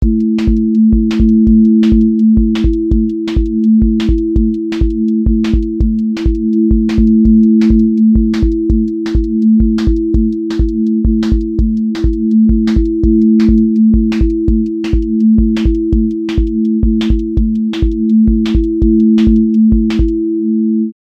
QA Listening Test drum-and-bass Template: dnb_break_pressure